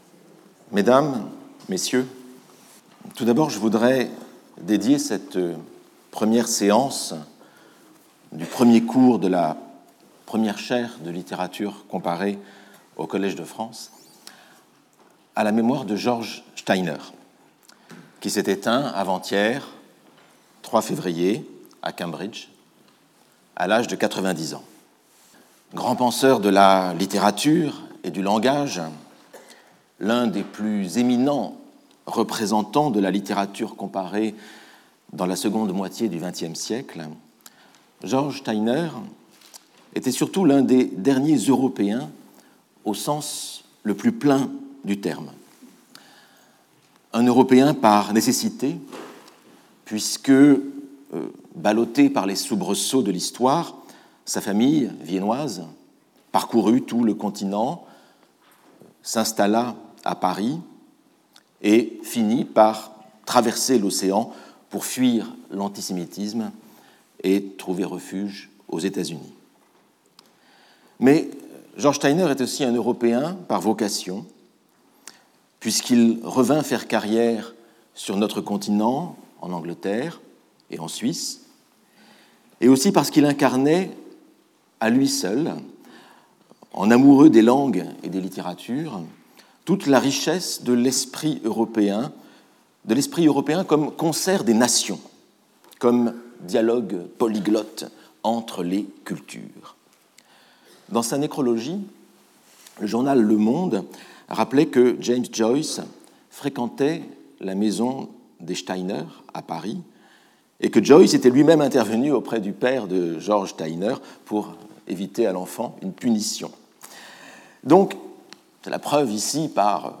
Cette première leçon, conçue comme une apostille de la leçon inaugurale, fait retour sur le poème « Les Conquérants » (1869) de José-Maria de Heredia, qui servit d’ouverture et de fil conducteur à la leçon inaugurale. On s’intéresse à la réception par le public de l’image finale du sonnet, celle des étoiles nouvelles.